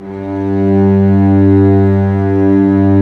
Index of /90_sSampleCDs/Roland L-CD702/VOL-1/STR_Vcs Bow FX/STR_Vcs Sordino